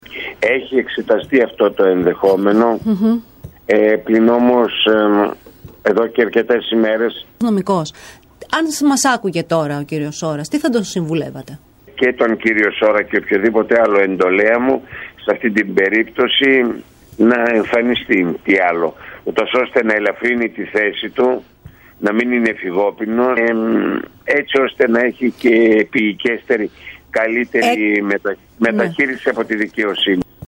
Μιλώντας σήμερα στην ΕΡΤ Πάτρας